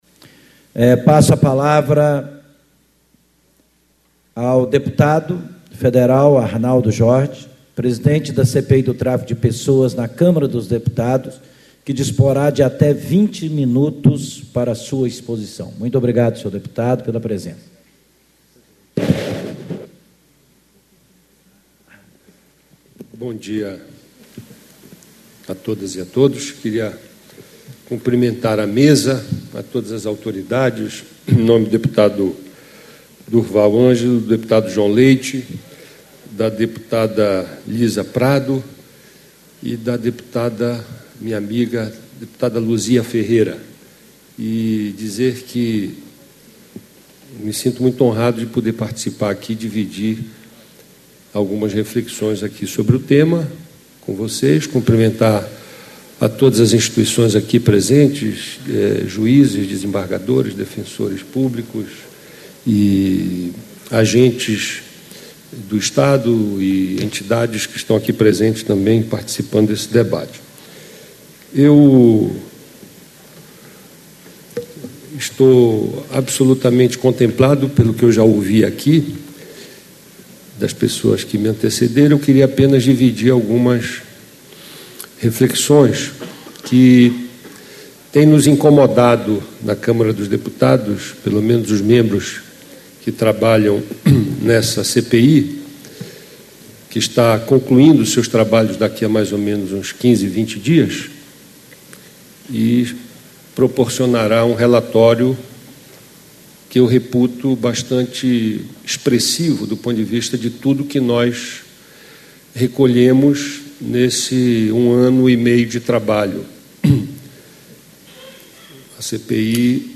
Painel: Tráfico de pessoas e Copa do Mundo no Brasil: o que precisamos saber - Arnaldo Jordy, Deputado federal e presidente da CPI do Tráfico de Pessoas na Câmara dos Deputados
Discursos e Palestras